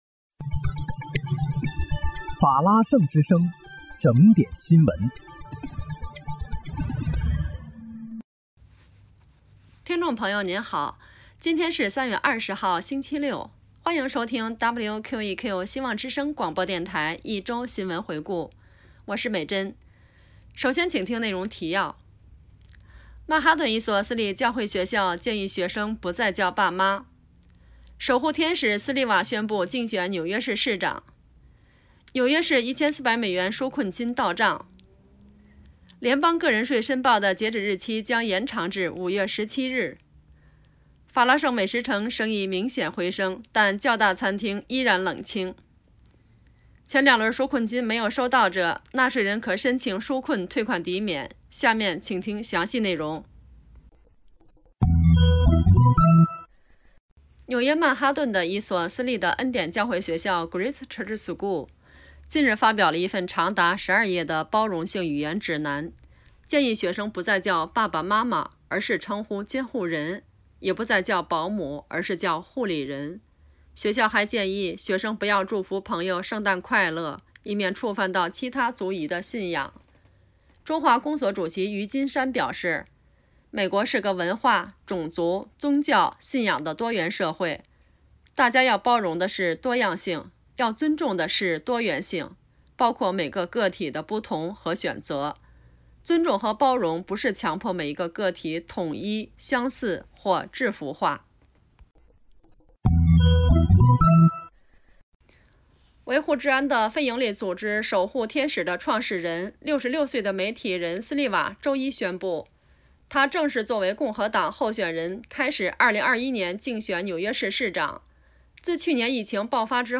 3月21日（星期日）一周新闻回顾